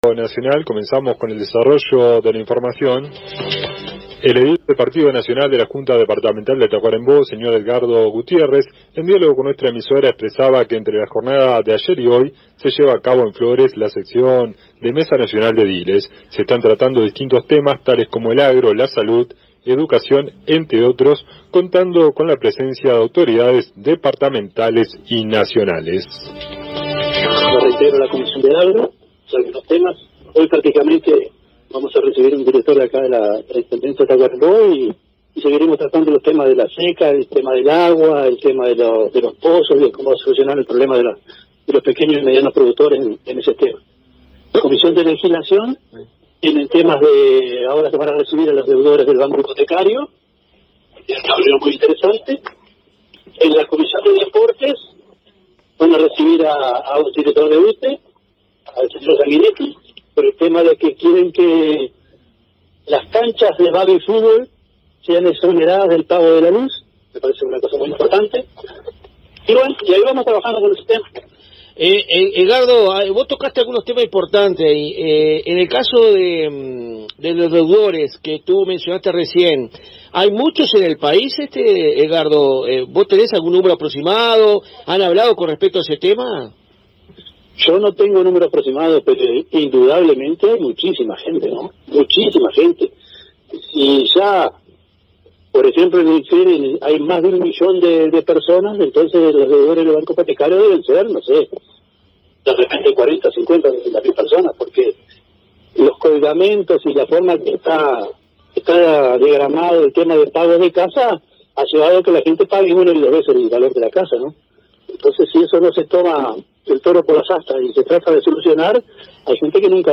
El vicepresidente de la Junta Departamental de Tacurembó, el edil isabelino Edgardo Gutiérrez, informó a la AM 1110 que se encuentra en Flores participando en una Mesa Nacional de Ediles, donde se están discutiendo importantes temas de interés nacional, como Agro, Salud, Educación, entre otros.